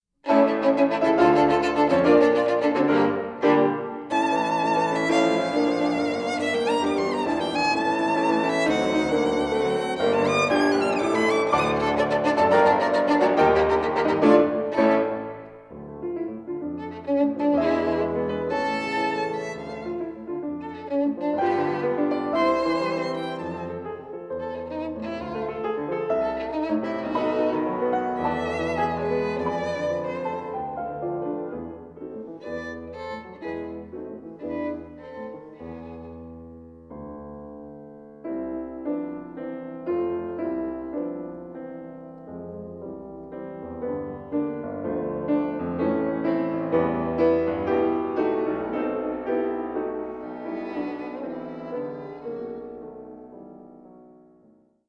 Stereo
violin
piano
Recorded in February 2013 at the Wells Fargo Auditorium, University of Nevada, Reno, USA